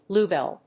音節blúe・bèll 発音記号・読み方
/ˈbluˌbɛl(米国英語), ˈblu:ˌbel(英国英語)/